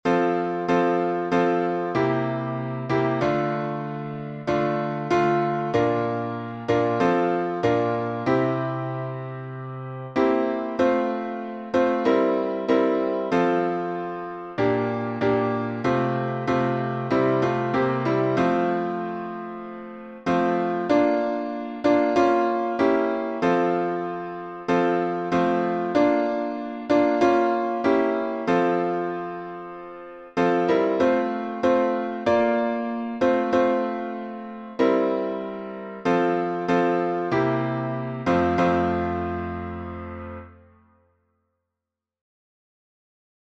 alternate harmonies
Key signature: F major (1 flat) Time signature: 4/4